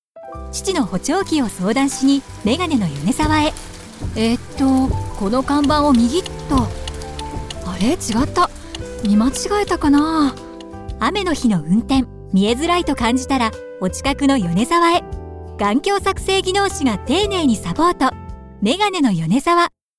12月1日より、メガネのヨネザワ様の新作CMの配信がradikoでスタートしました。